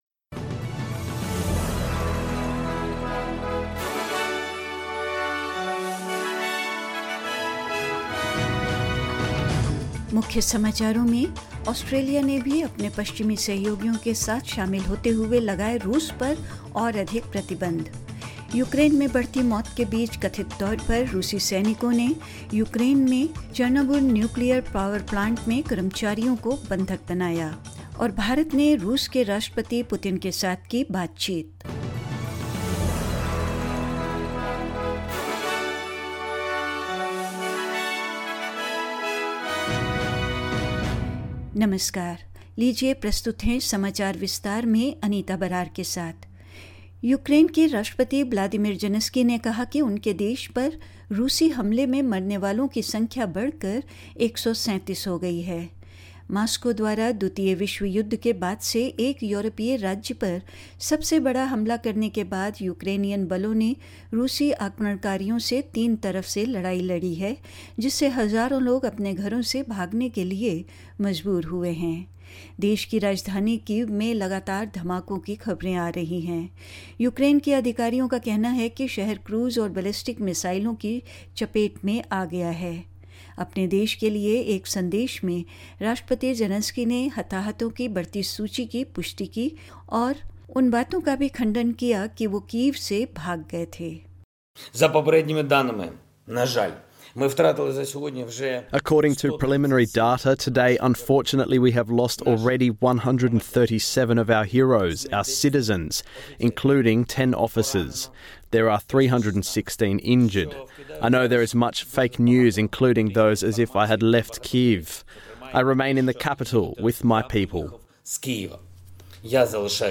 In this latest SBS Hindi bulletin: Australia joins its western allies in enacting further sanctions against Russia; Russian soldiers said to be holding staff hostage at the Chernobyl nuclear power plant amid a rising death toll in Ukraine; Backlash over the Russian invasion spills over into the sporting community and more news